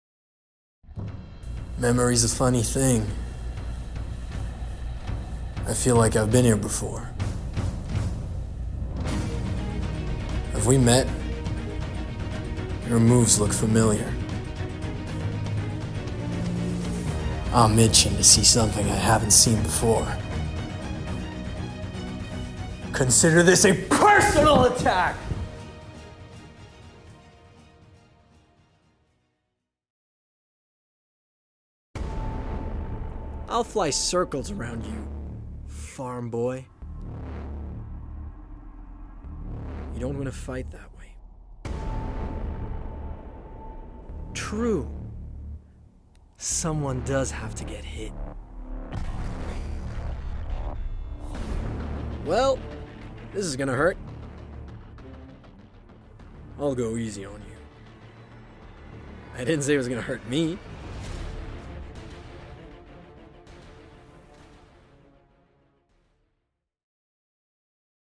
Voix pour Jeux Vidéo
16 - 35 ans - Baryton